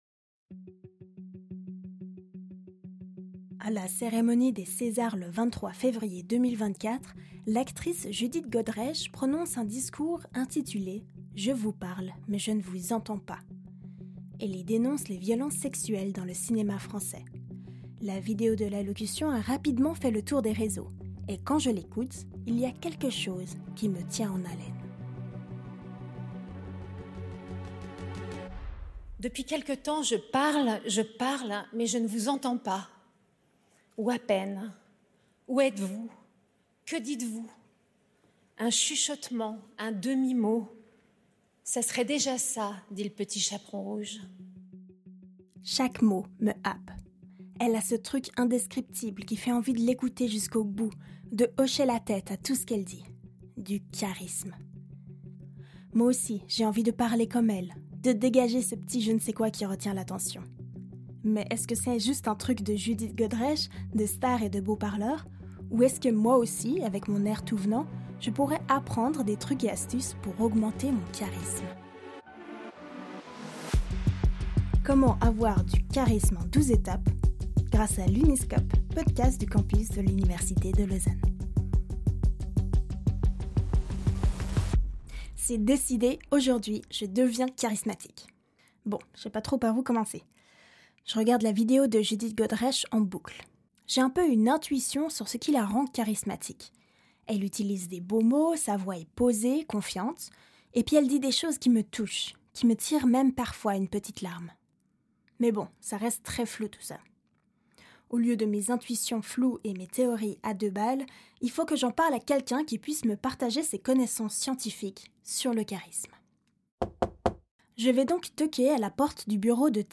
Extraits de discours
Musiques